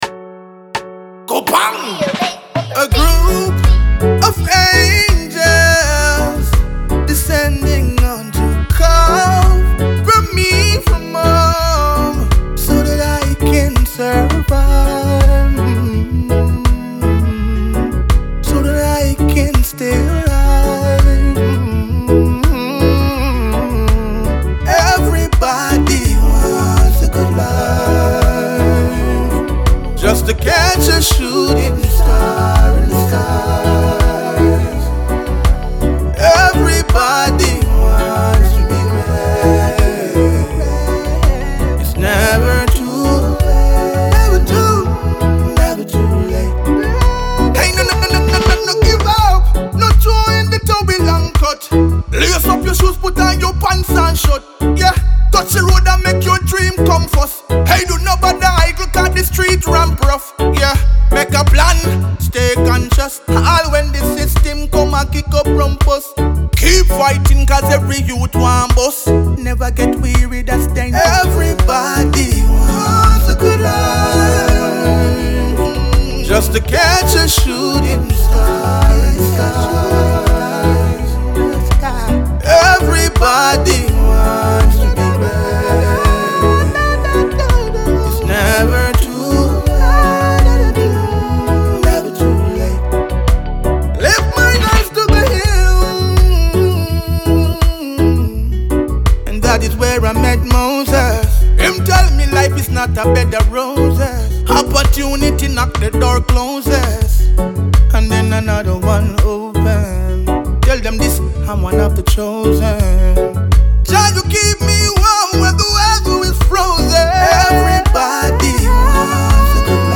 Reggae RiddimRiddim